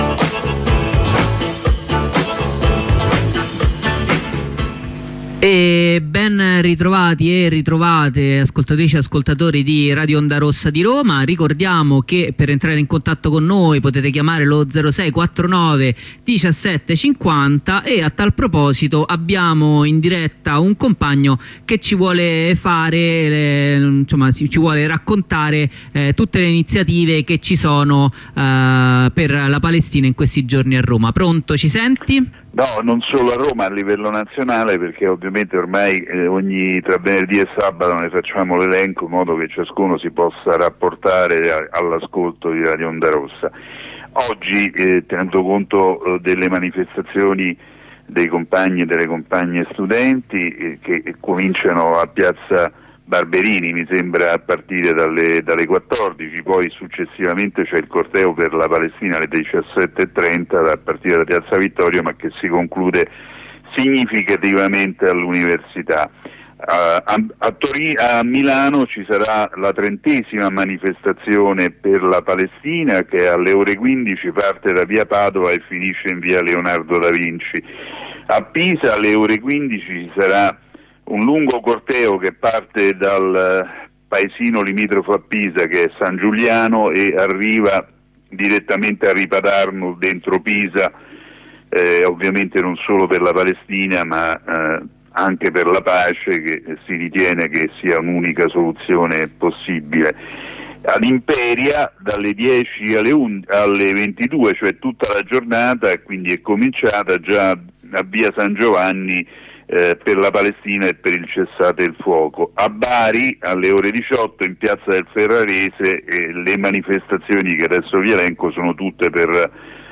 Corrispondenza con un'avvocata del Legal Team, dopo la mattinata di identificazioni